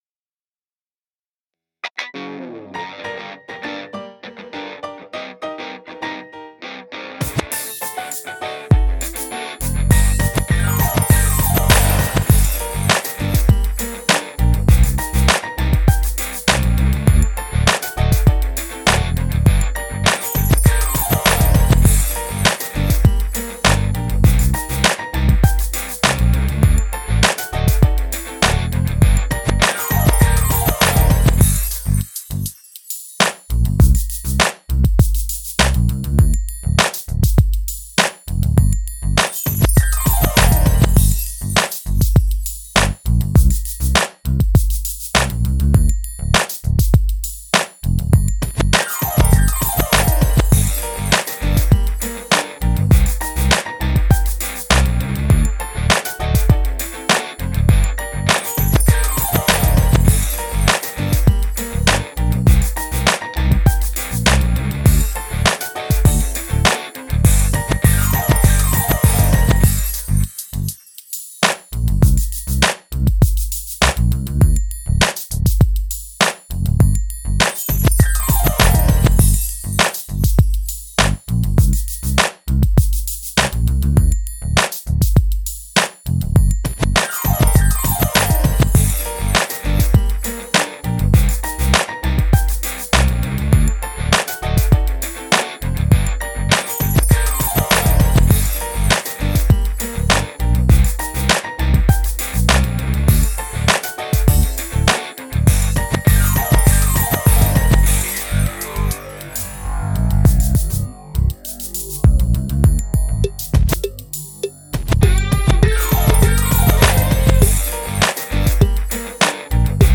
Playback Personnalisé